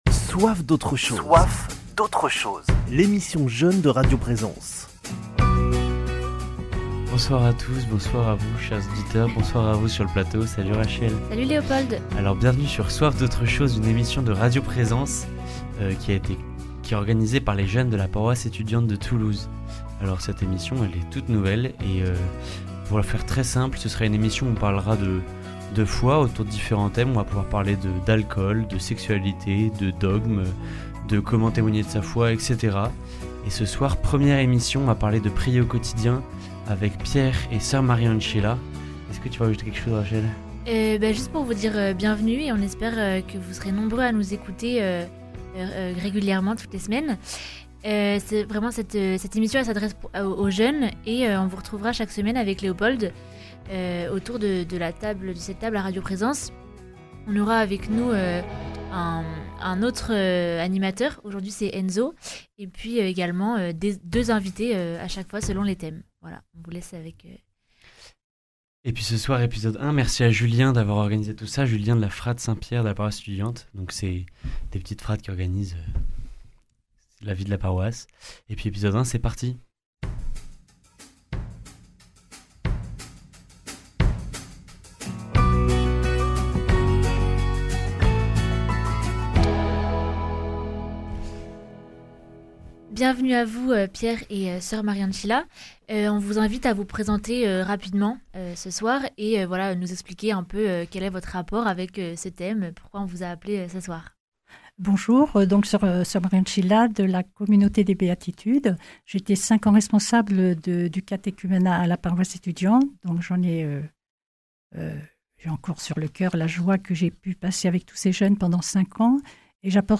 Découvrez les témoignages touchants de nos deux invités.
Interlude musicale